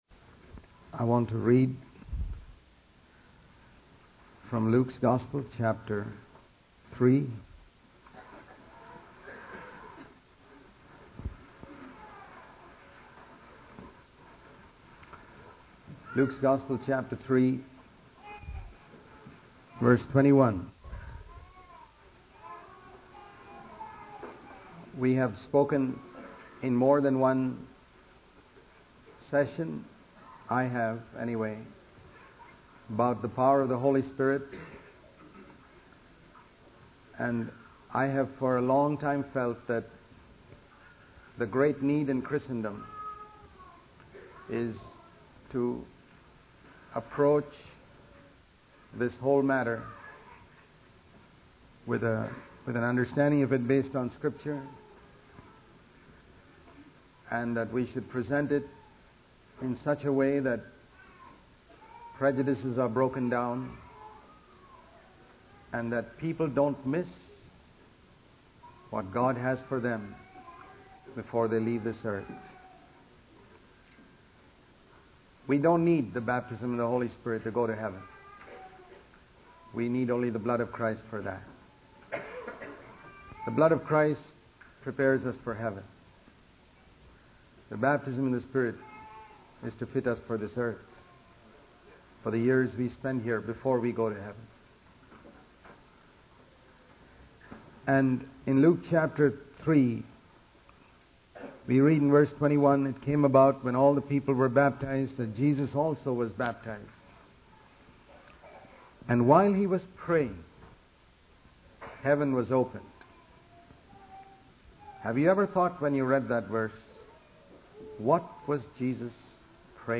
In this sermon, the preacher emphasizes the importance of making a choice to follow God's way. He highlights the concept of falling into the ground and dying, which leads to bearing much fruit.